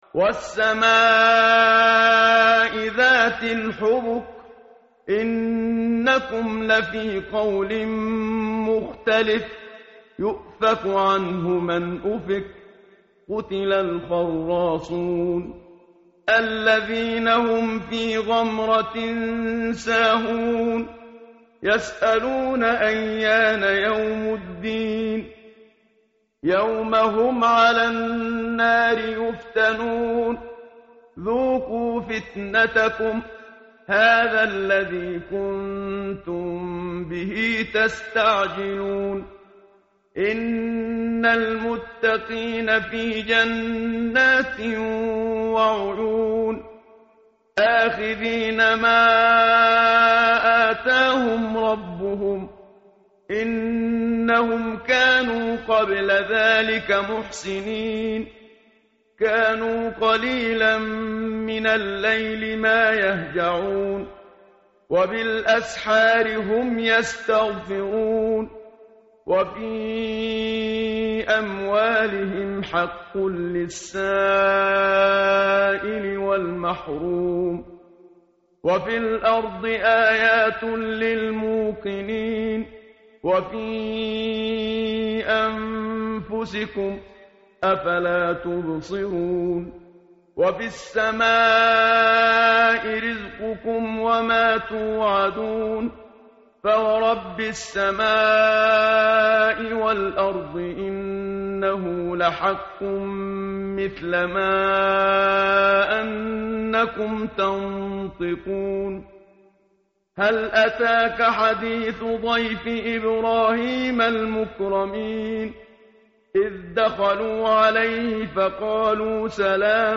متن قرآن همراه باتلاوت قرآن و ترجمه
tartil_menshavi_page_521.mp3